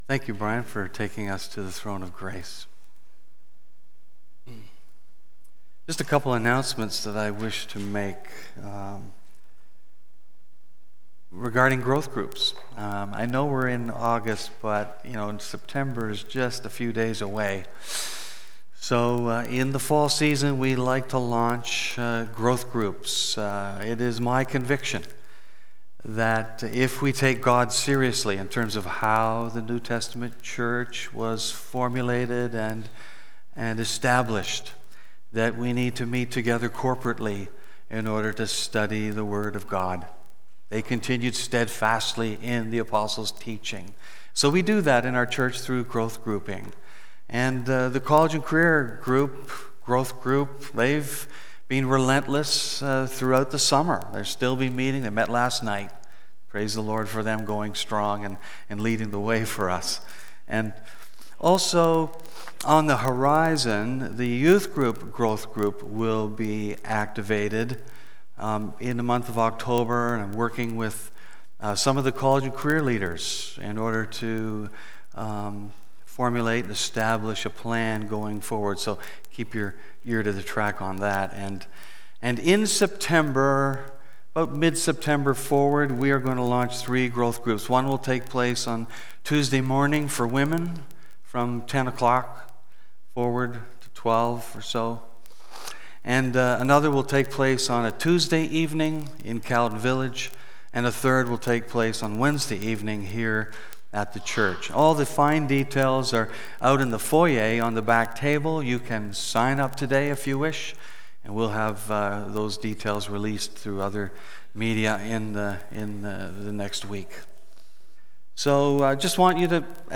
Part 73 BACK TO SERMON LIST Preacher